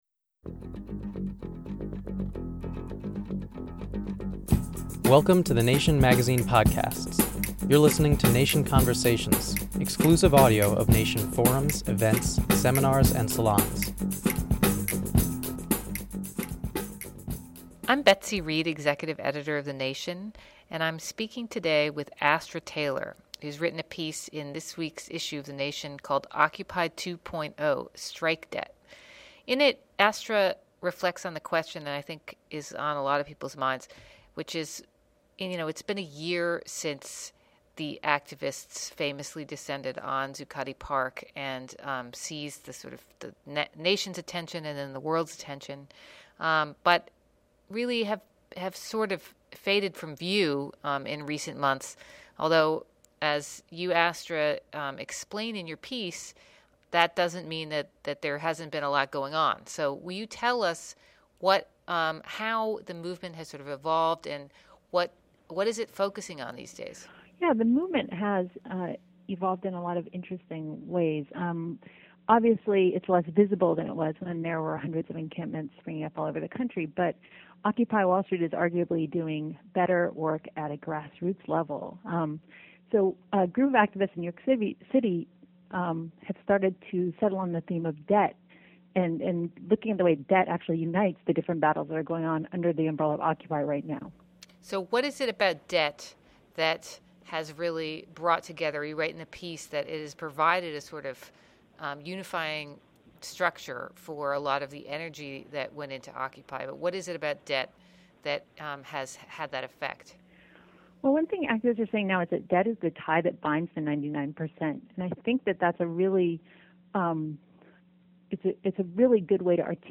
As Occupy evolves from a short-term protest to a long-term movement, some activists see debt becoming the new "tie that binds the 99 percent." In the build-up to Occupy’s first anniversary, journalist and documentary filmmaker Astra Taylor joined Nation editor Betsy Reed to ask the question: if we’re all facing massive debt, why are we still so ashamed of it?